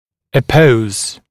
[æ’pəuz][э’поуз]смыкать, соединять, сближать